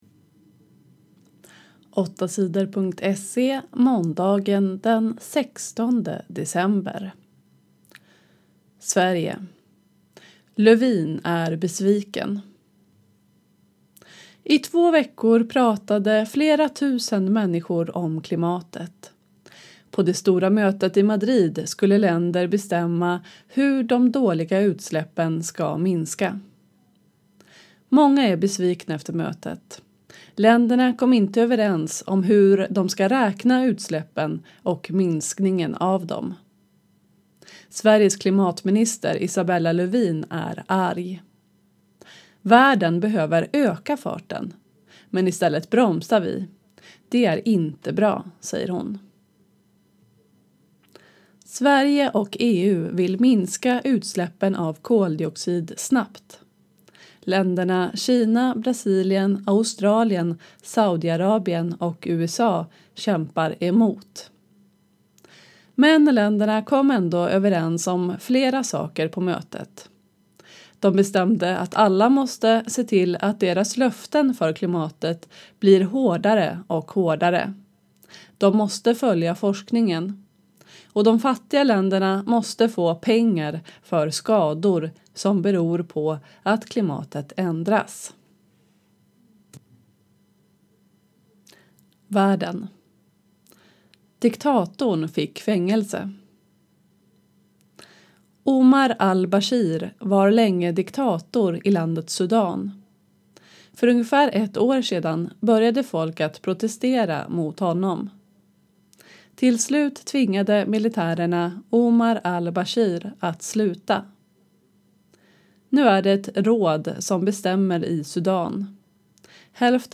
8 Sidor gör nyheter på lätt svenska.